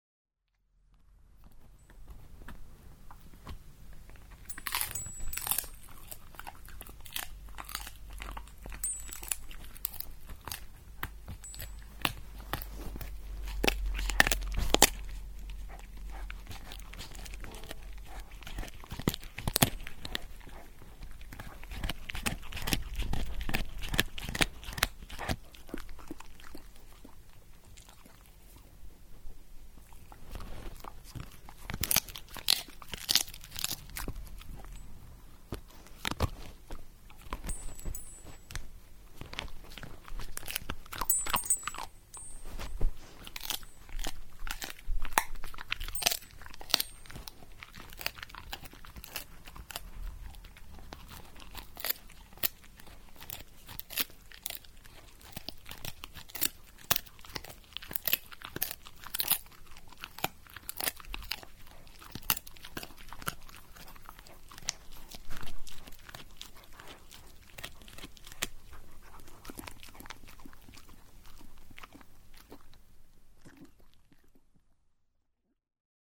She also liked nothing better than a tasty pig’s ear as a snack, and woke me up at 6am the last two nights I stayed at home by crunching said treat under the table with great gusto.
I recorded the sound in the end, since I had to lie there and listen to it!
jenny-eating-pig's-ear.mp3